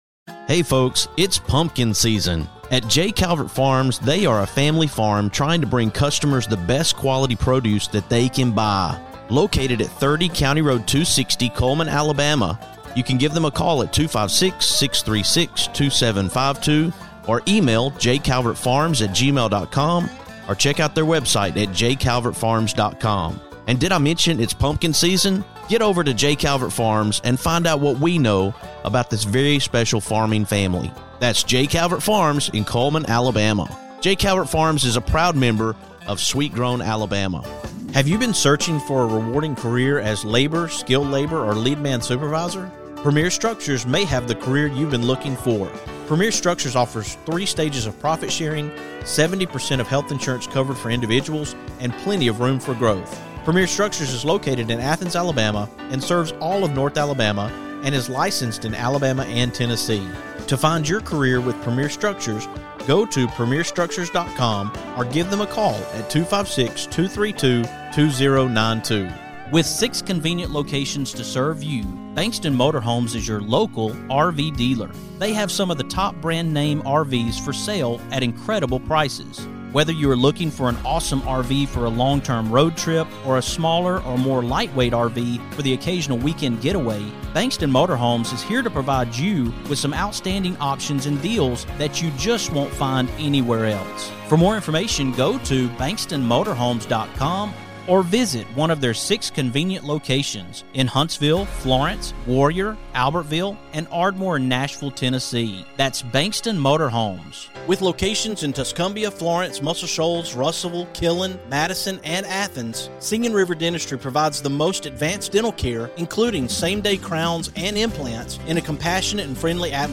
Tune in for an honest and heartfelt conversation that may be just what someone needs to hear tonight.